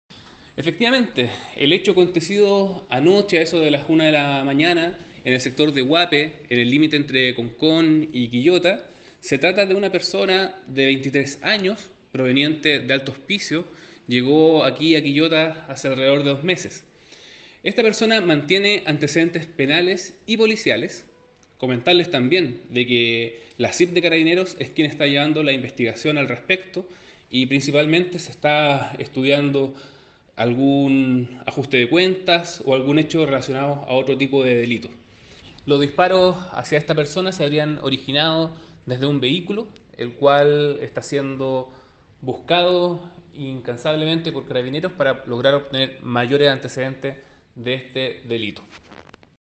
Delegado-Presidencial-Provincial-de-Quillota-sobre-hombre-de-23-anos-baleado.mp3